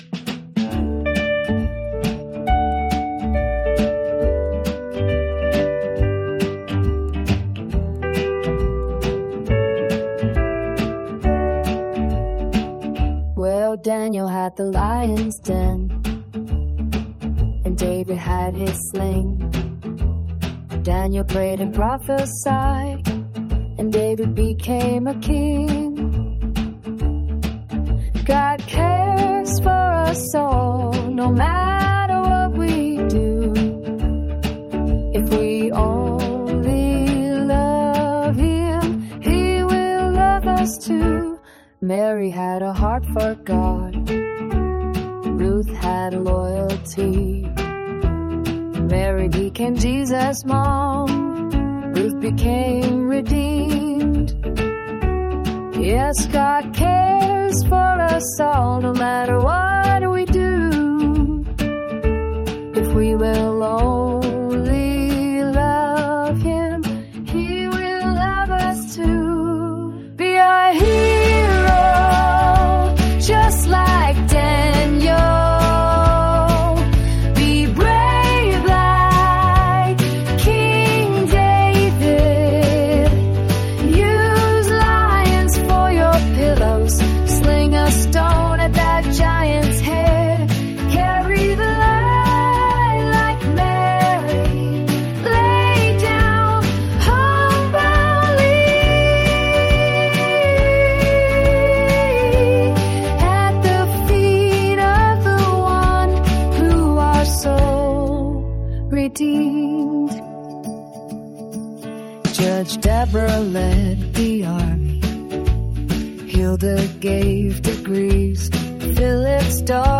Sung with all the warble and trill of a passionate songbird!
Finally I tried an ai app that will use your lyrics and description and even your melody and create demos like this one!